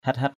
/hɛt-hɛt/ (t.) chang chang = torride. pandiak hait-hait pQ`K =hT-=hT nắng chang chang = d’une chaleur torride.